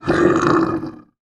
roar.wav